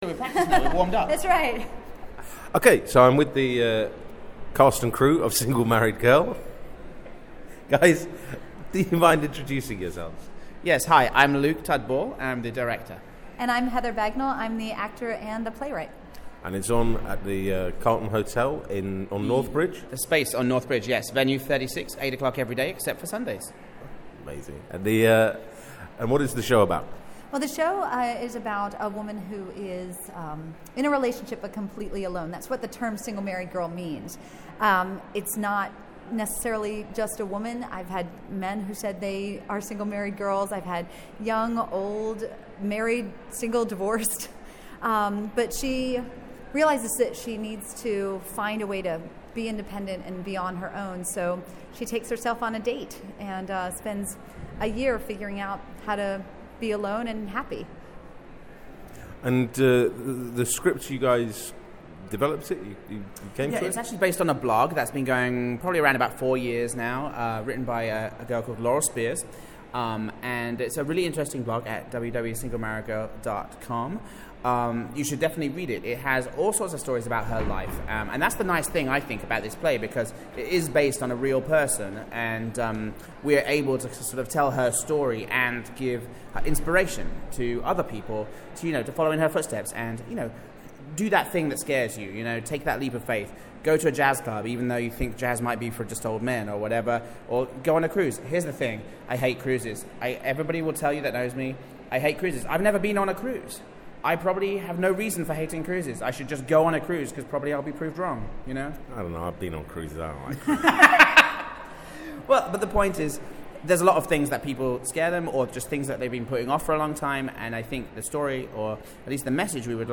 Audio Interviews, Edinburgh Festivals 2013, Edinburgh Fringe 2013